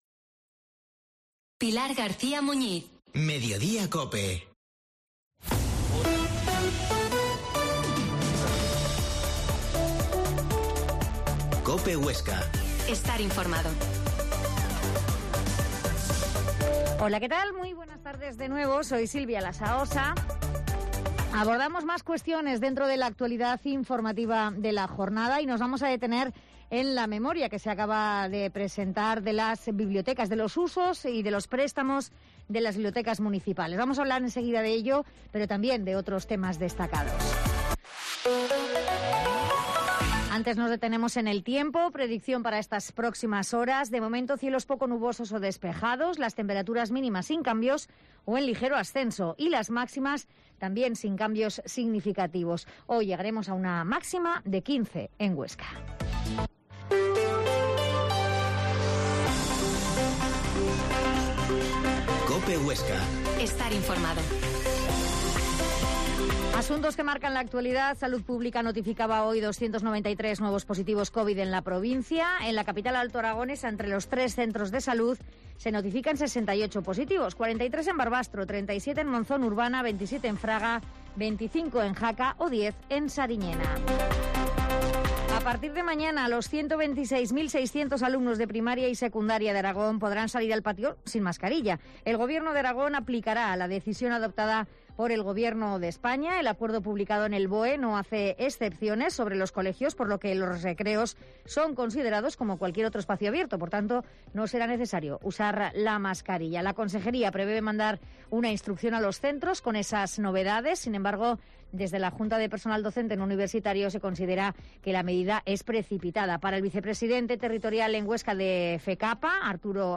La Mañana en COPE Huesca - Informativo local Mediodía en Cope Huesca 13,50h.